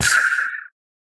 Minecraft Version Minecraft Version 25w18a Latest Release | Latest Snapshot 25w18a / assets / minecraft / sounds / mob / pufferfish / death1.ogg Compare With Compare With Latest Release | Latest Snapshot
death1.ogg